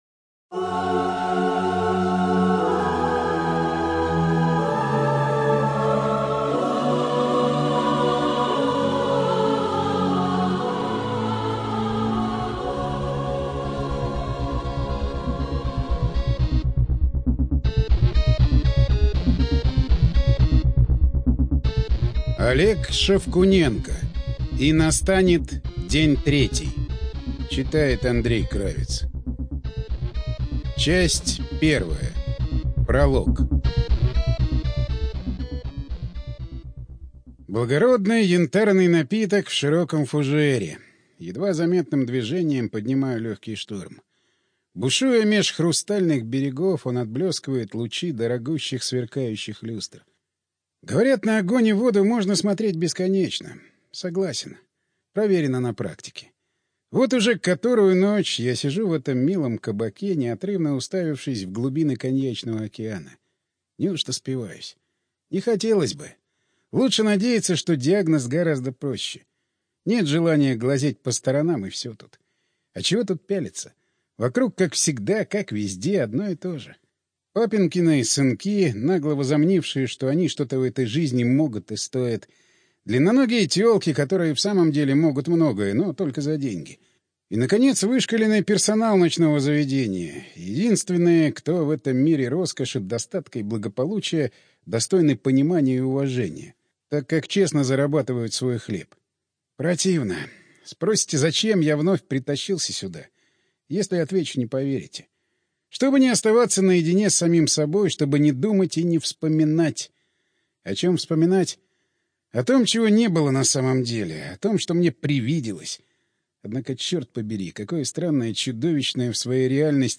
ЖанрФантастика, Ужасы и мистика